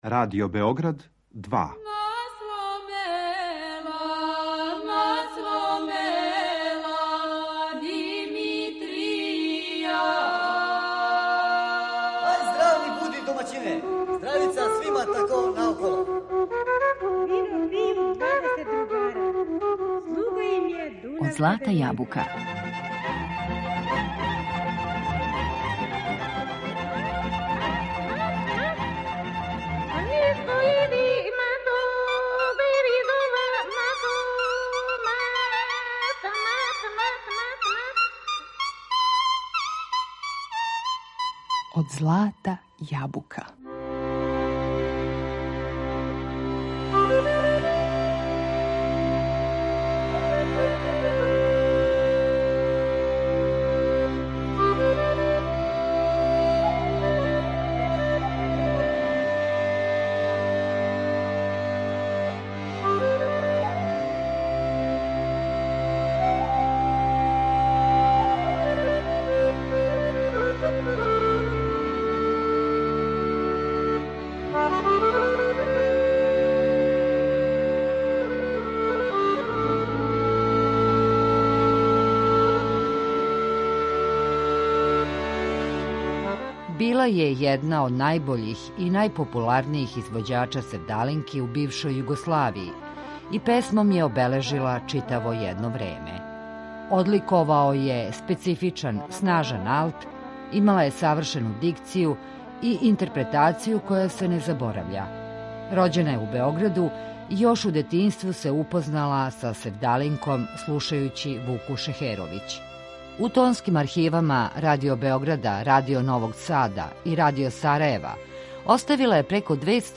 Одликовао је специфичан, снажан алт, имала је савршену дикцију и интерпретацију која се не заборавља.